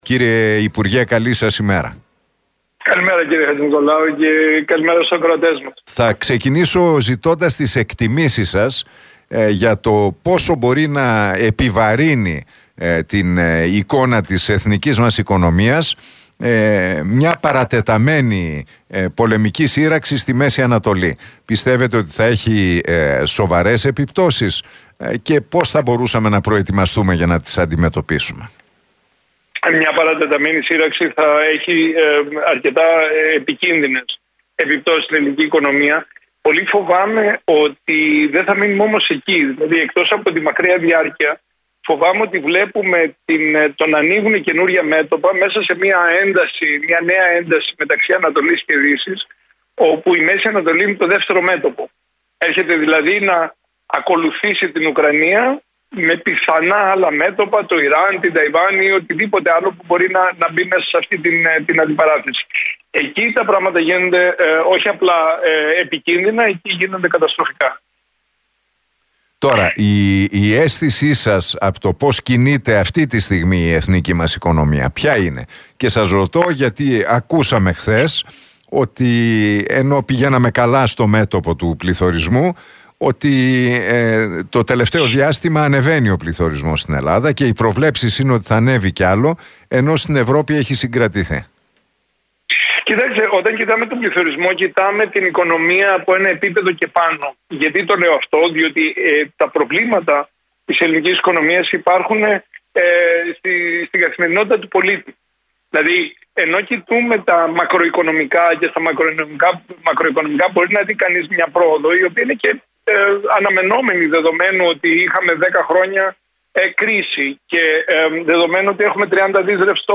Για επικίνδυνες επιπτώσεις στην ελληνική οικονομία από μια παρατεταμένη σύρραξη στην Μέση Ανατολή έκανε λόγο ο Παύλος Γερουλάνος μιλώντας στον Realfm 97,8 και την εκπομπή του Νίκου Χατζηνικολάου.